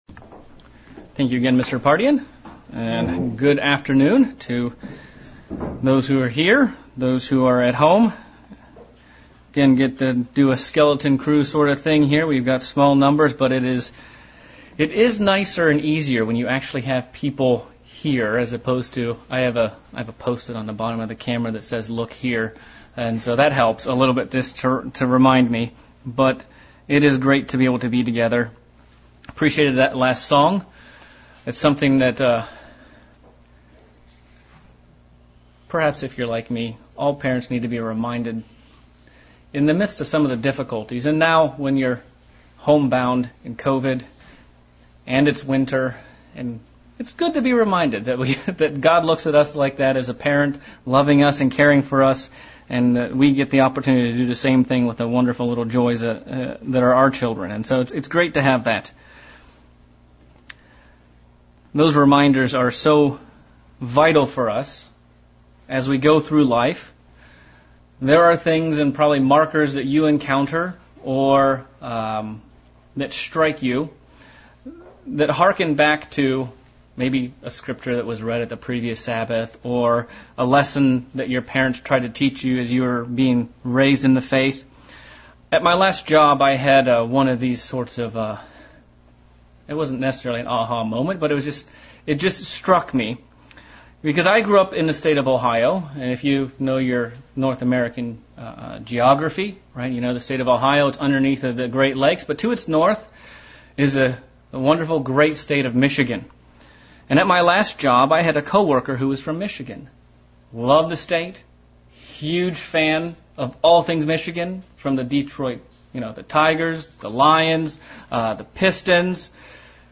Split sermon looking at the classification of sin.